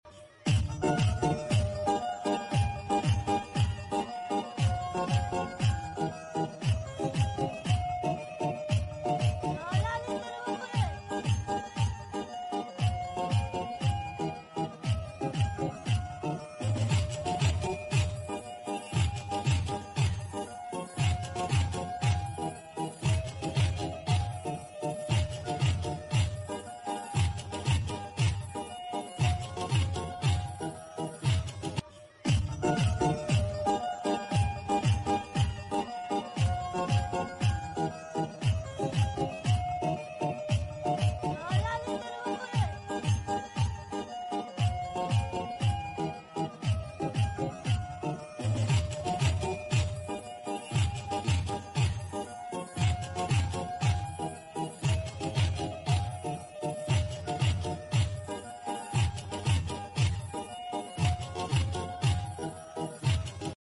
Sad 8k Song